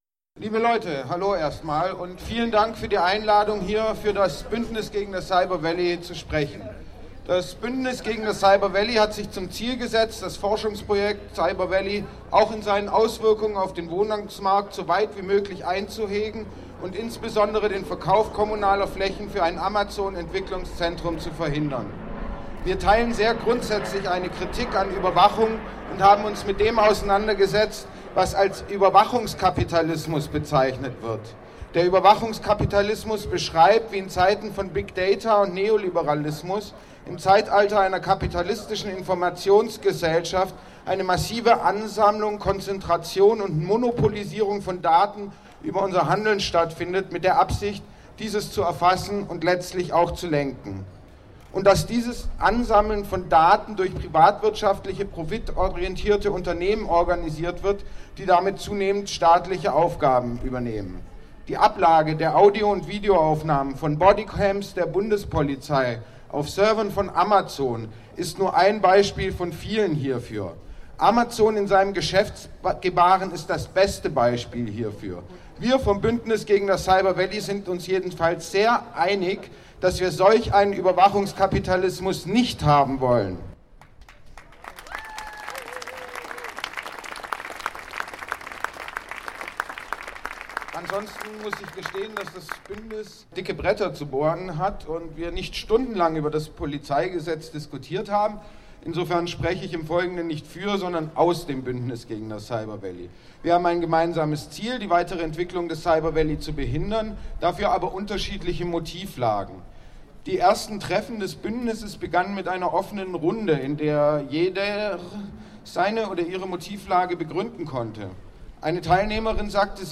Die Kundgebung vom 27.04.2019 in Tübingen, richtete sich gegen die Planungen, das Polizeigesetz in Baden-Württemberg erneut zu verschärfen.
Auch ohne Sonnenschein waren ca. 250 bis 300 Menschen, gegen die erneute Verschärfung der Polizeigesetze, in Tübingen auf der Straße.
Beitrag vom NO CYBER VALLEY Bündnis